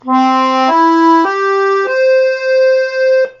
Asymmetrische „Musette“-Stimmung